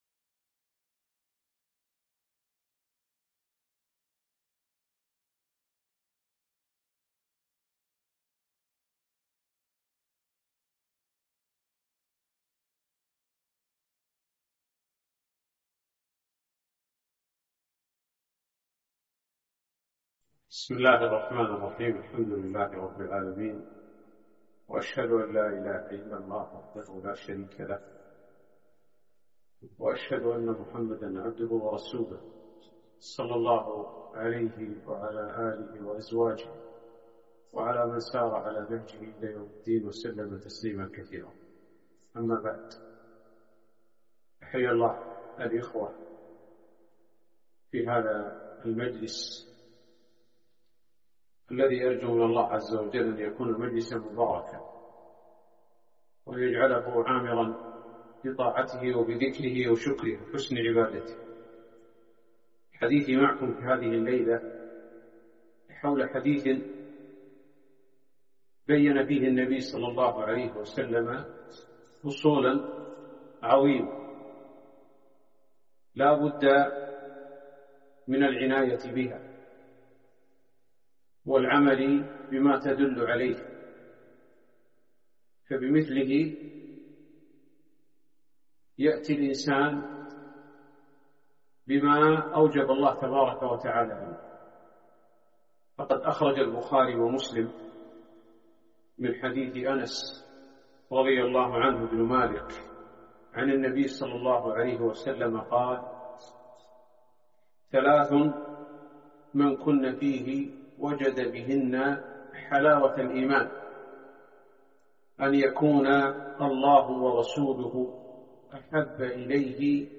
كلمة - حلاوة الإيمان